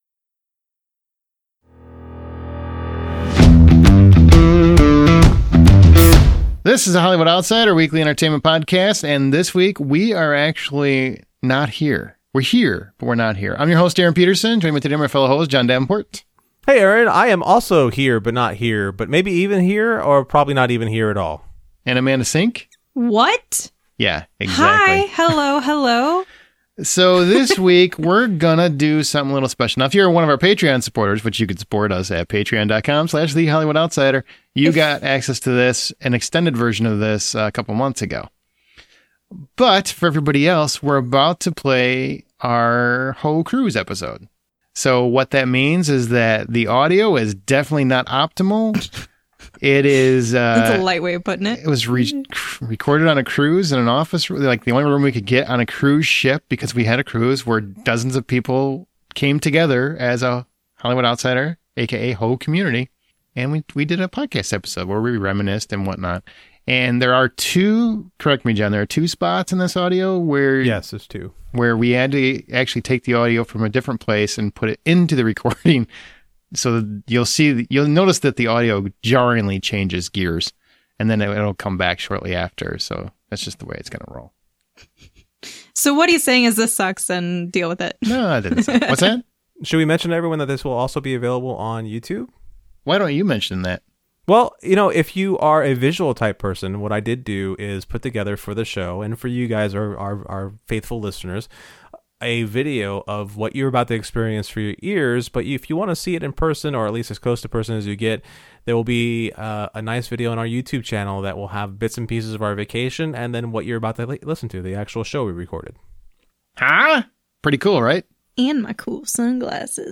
For this episode of our show, we're taking you on Royal Caribbean's Harmony of the Seas with us, presenting you with the live recording of the actual gathering of every Hollywood Outsider host as we reminisce, recollect, and most of all reflect on what it has been like to be part of the show. Whether you're a new listener or have been here since the beginning, this is a lighthearted and jovial time you won't want to miss...and it's delivered to you straight from international waters!